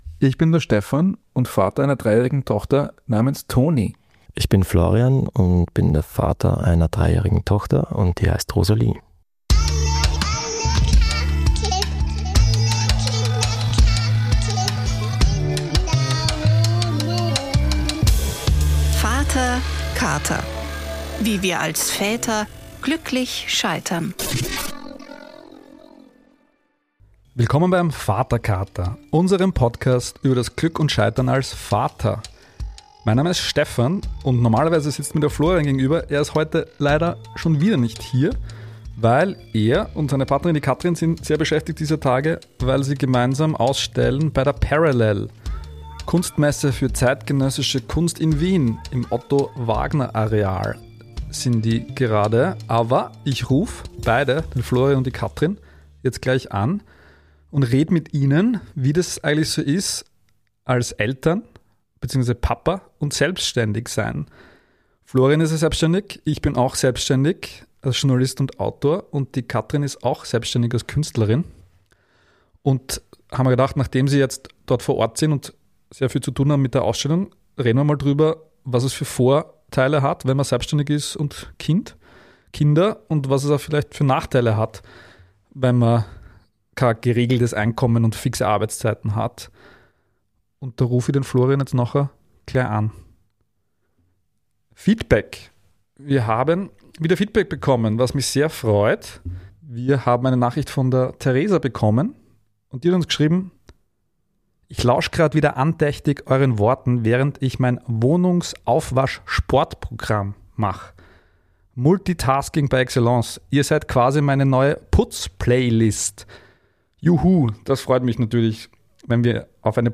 Live von der Parallel-Kunstmesse am Otto-Wagner-Areal in Wien.